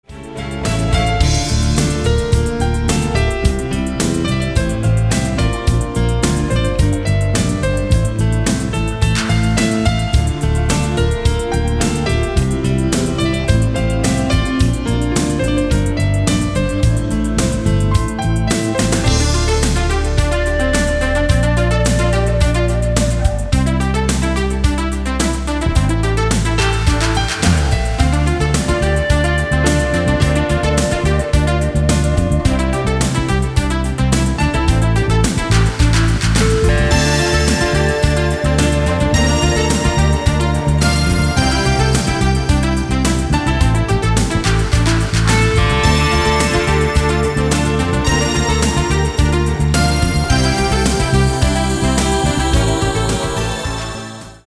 • Качество: 320, Stereo
женский голос
электронная музыка
спокойные
без слов
клавишные
инструментальные
пианино